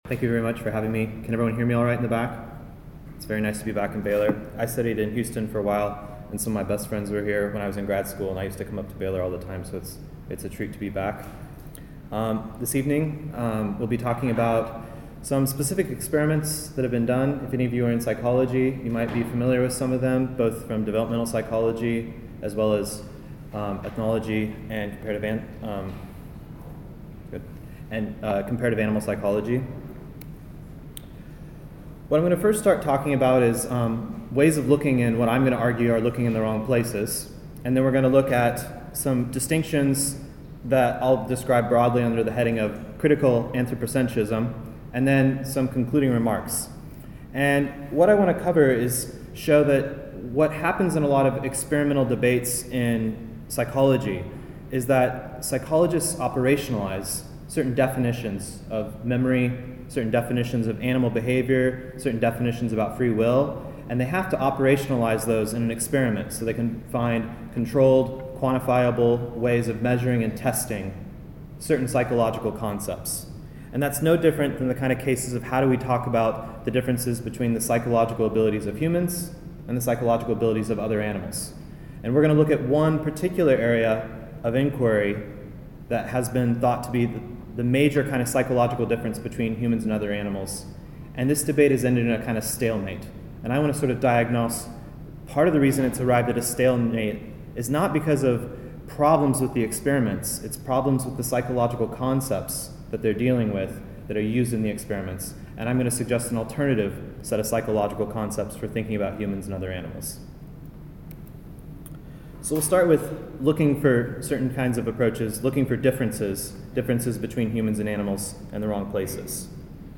This talk was given on November 20, 2019 at Baylor University.